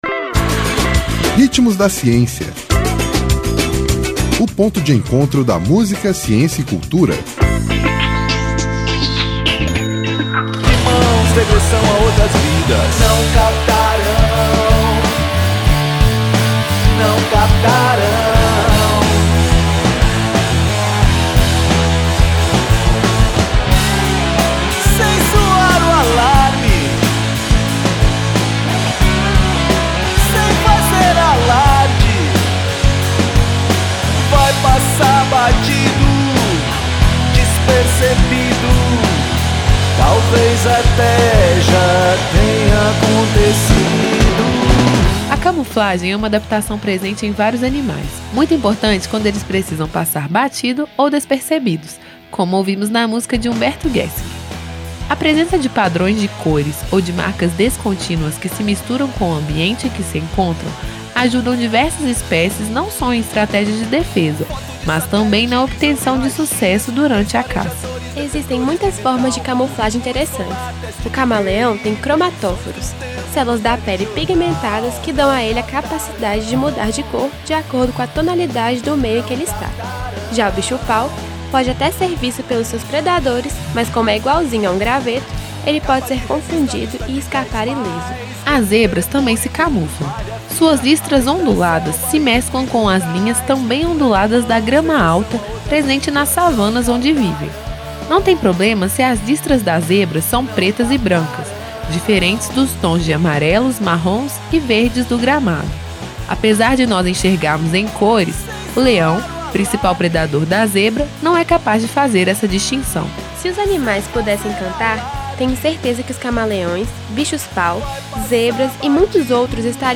A camuflagem é uma adaptação presente em vários animais, muito importante quando eles precisam passar batido ou despercebidos, como ouvimos na música de Humberto Gessinger. A presença de padrões de cores ou de marcas descontínuas que se misturam com o ambiente em que se encontram ajuda diversas espécies não só em estratégias de defesa, mas também na obtenção de sucesso durante a caça…Ouça todo o programa:
Intérprete: Engenheiros do Hawaii